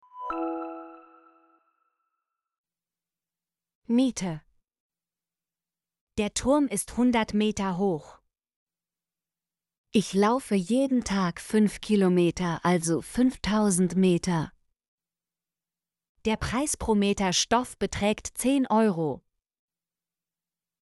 meter - Example Sentences & Pronunciation, German Frequency List